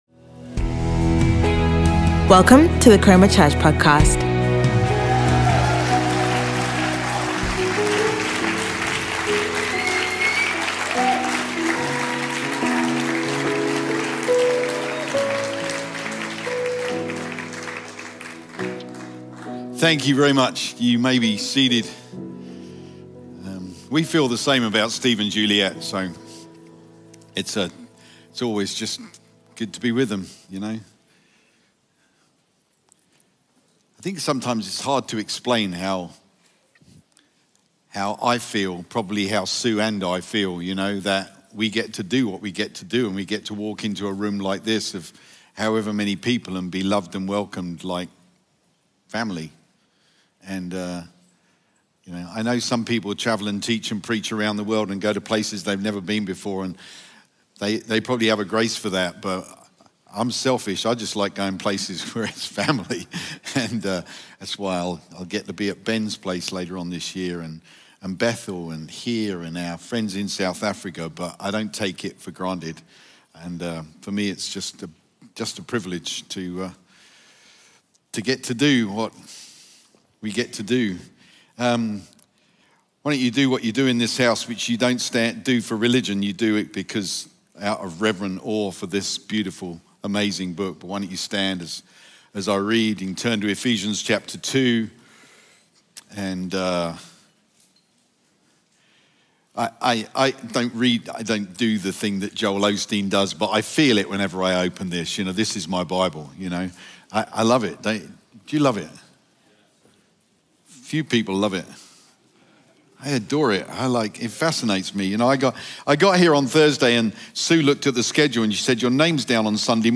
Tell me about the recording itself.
Chroma Church Live Stream